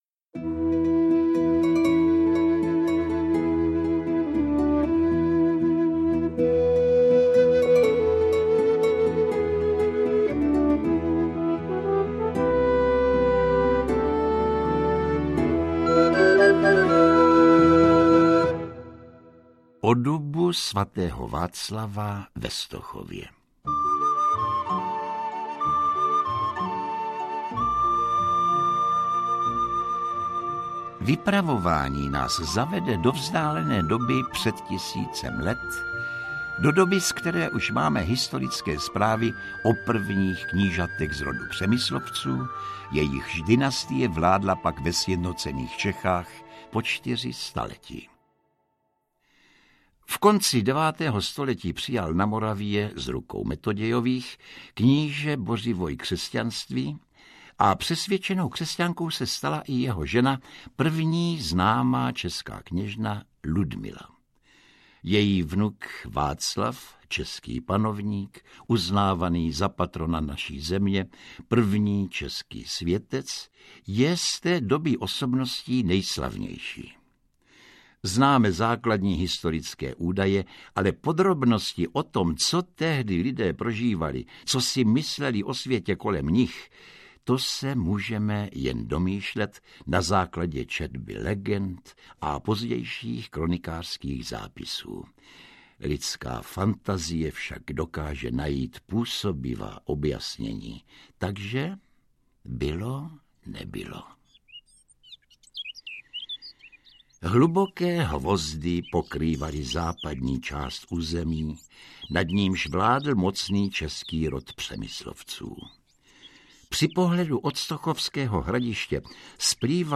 Příběhy památných stromů audiokniha
Příběhy živých svědků událostí z různých míst naší země vypráví Josef Somr, Bára Hrzánová, Jiří Lábus a dalších oblíbení herci.
Ukázka z knihy